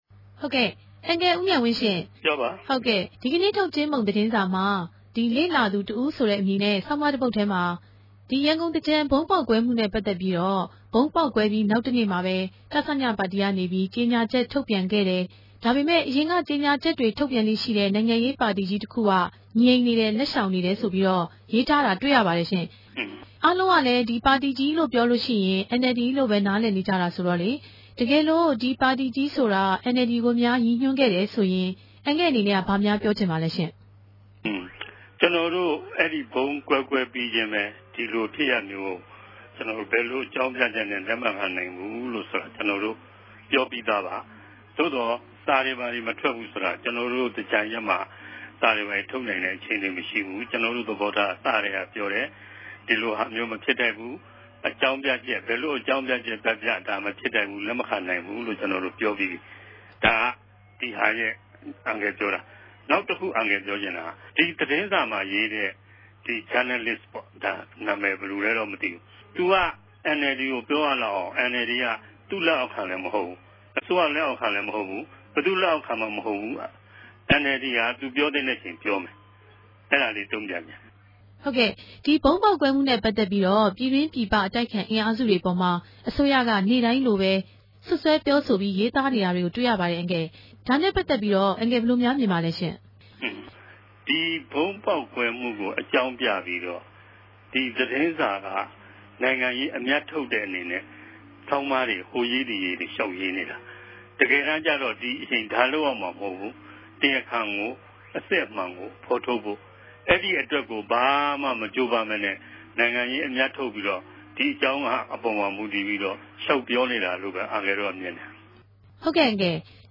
ဆက်သြယ်မေးူမန်းခဲ့ပၝတယ်၊၊